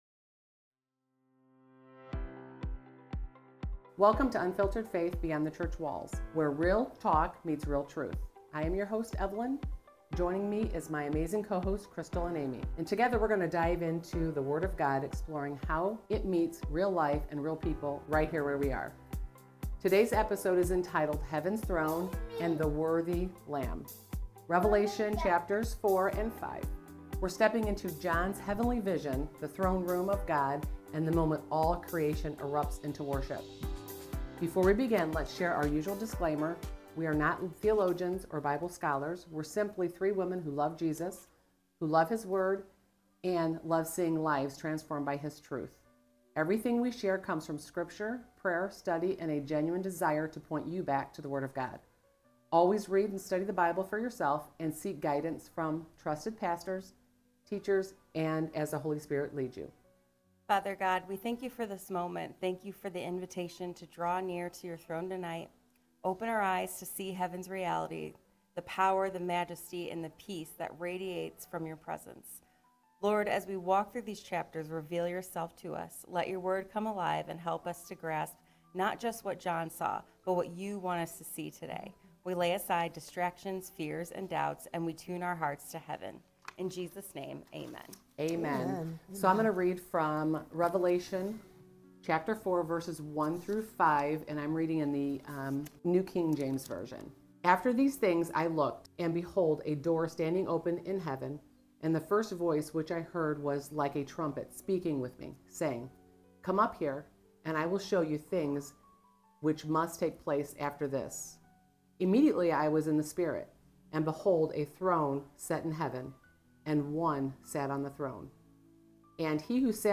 Revelation 4–5 Bible Study | The Throne Room of Heaven